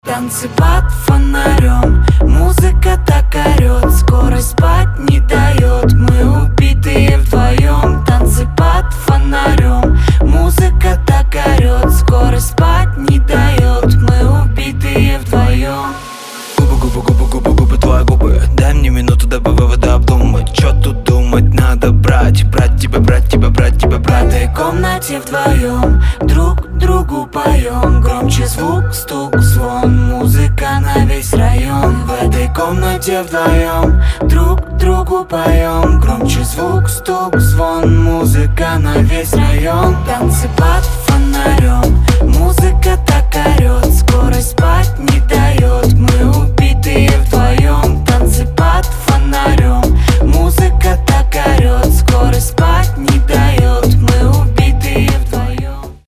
Драйвовые
dance
дуэт
club
чувственные
клубнячок